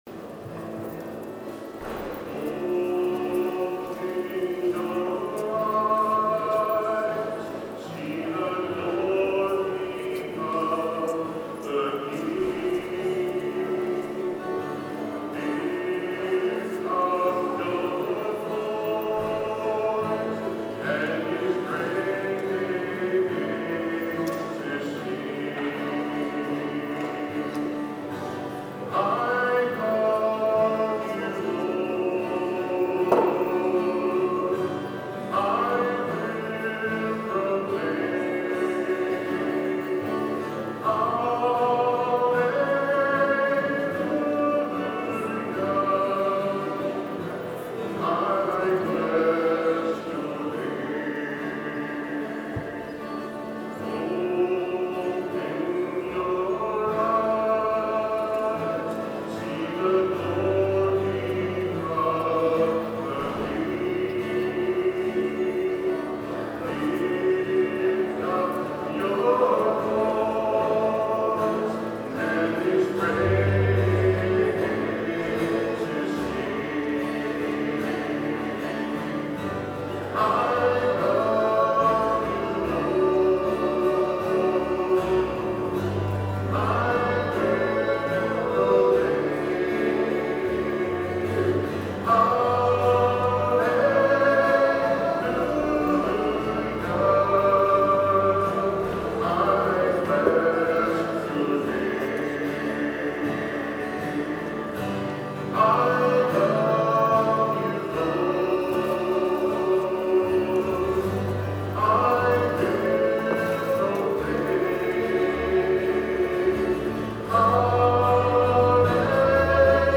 10/20/13 10:30 Mass Recording of Music
You can hear it somewhat through what the mics pickup. Even when it is turned on for the last song, overall we still lack "balance" in general. 102013_All_Music.mp3